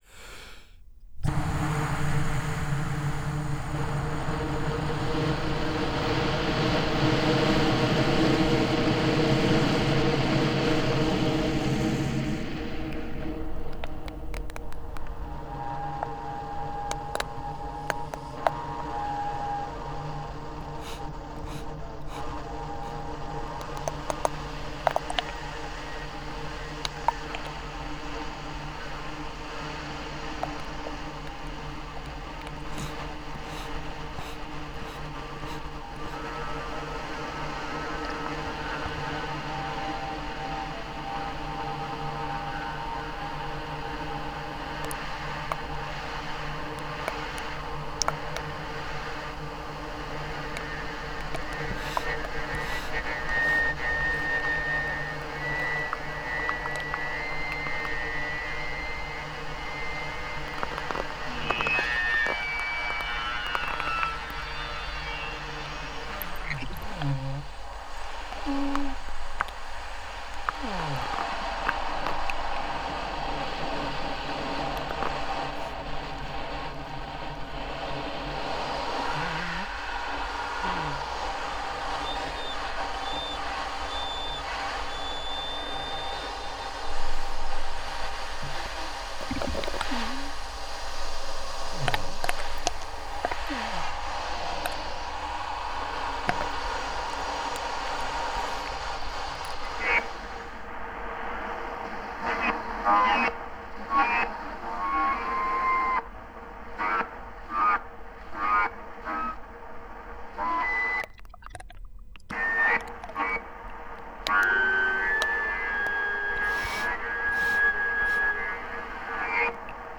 So the circular breathing produces a continuous sound.